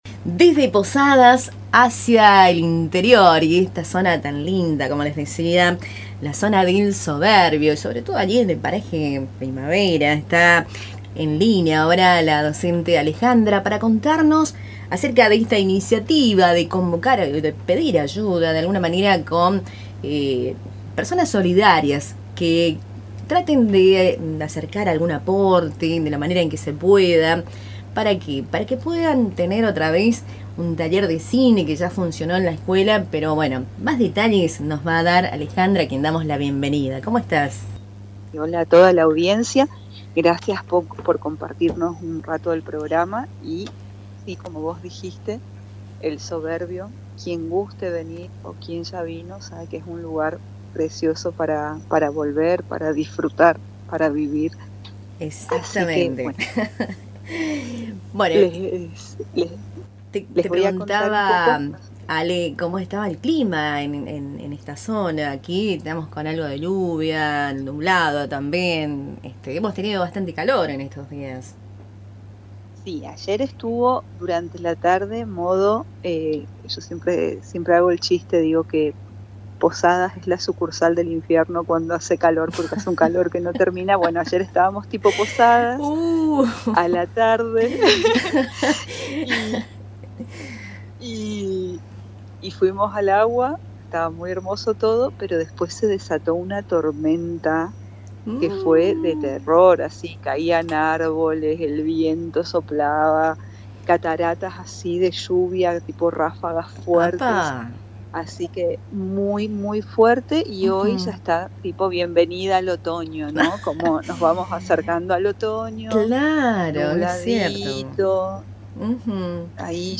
Según explicó durante la entrevista, actualmente no existen líneas de financiamiento ni programas específicos que permitan sostener el taller desde el sistema educativo formal. Ante este escenario, la comunidad decidió apelar a la solidaridad de vecinos, amigos y personas vinculadas al ámbito cultural para reunir los recursos necesarios.